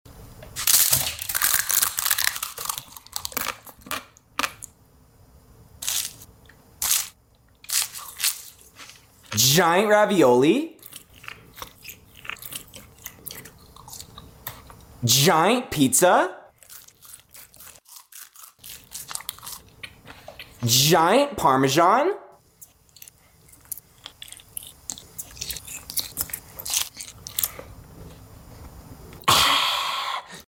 Giant Italian Food ASMR!? 🇮🇹🤤 Sound Effects Free Download